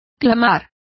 Complete with pronunciation of the translation of cried.